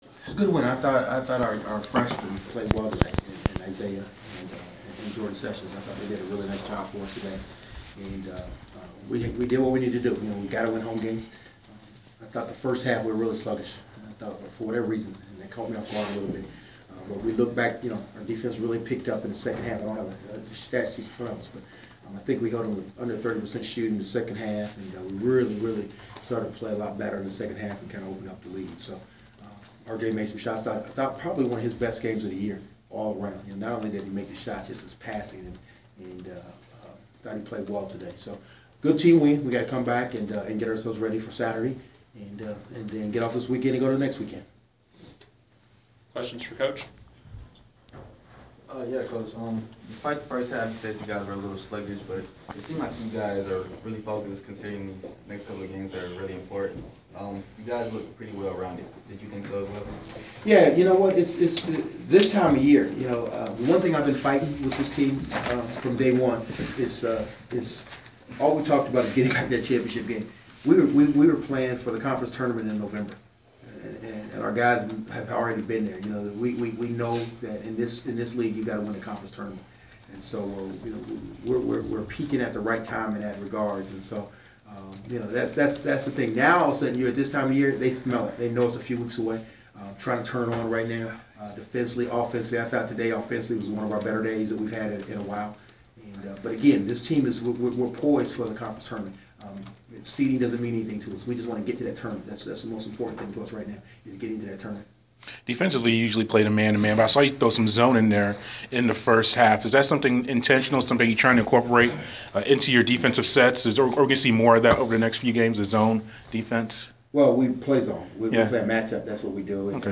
Inside the Inquirer: Postgame presser with Georgia State’s R.J. Hunter (2/19/15)
We attended the postgame presser of Georgia State guard R.J. Hunter following his team’s 79-51 home win over South Alabama on Feb. 19.